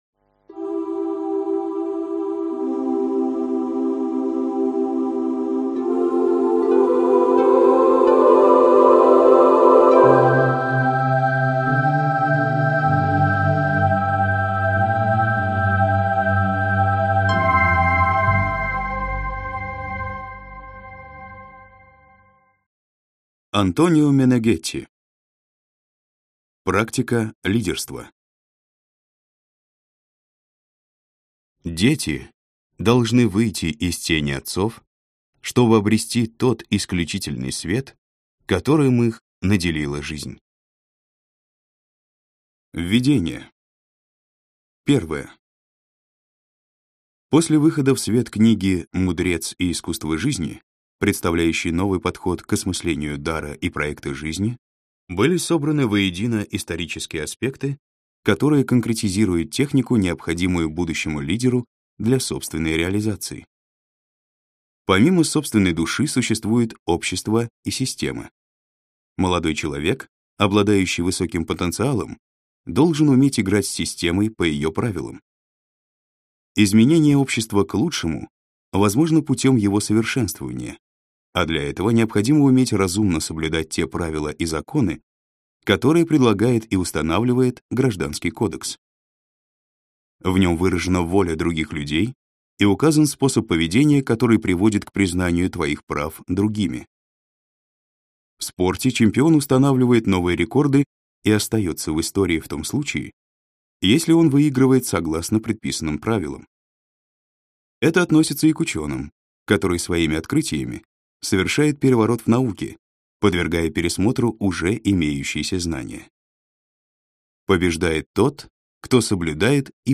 Аудиокнига Практика лидерства | Библиотека аудиокниг